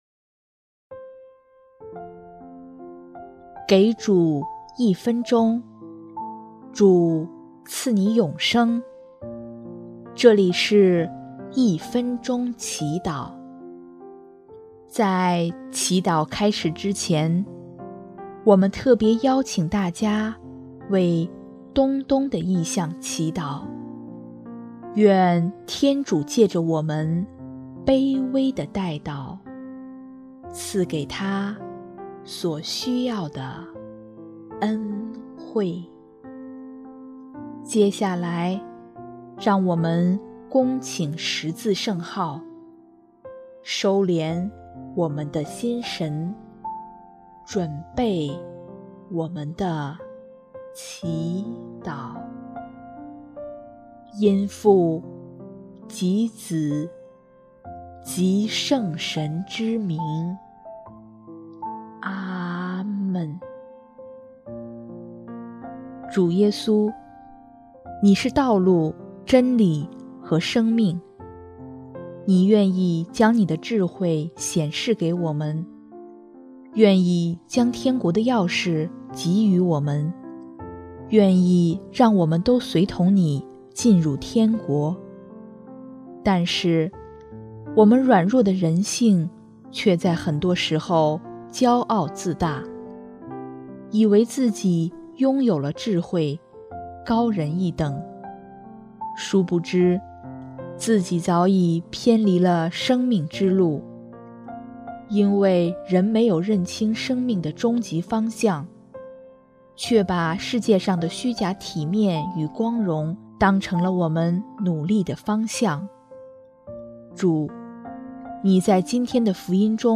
【一分钟祈祷】|10月19日 弃绝人性的弱点，学得真正的智慧！